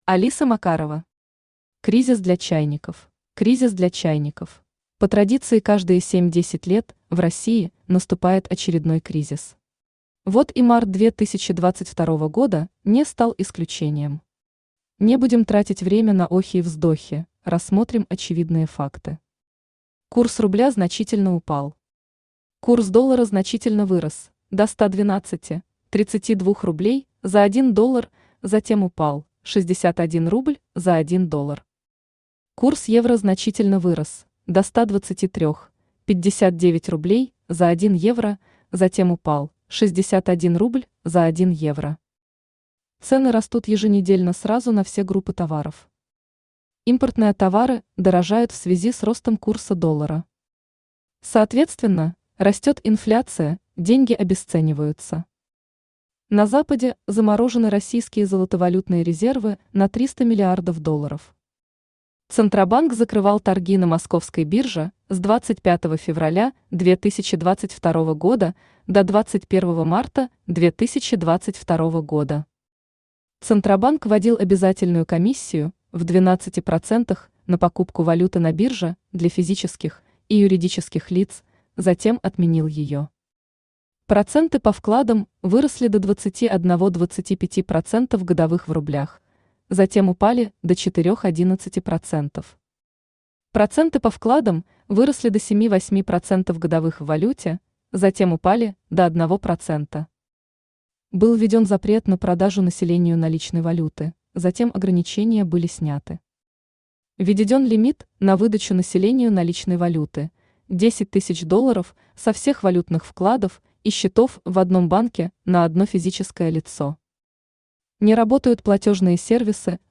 Aудиокнига Кризис для чайников Автор Алиса Макарова Читает аудиокнигу Авточтец ЛитРес.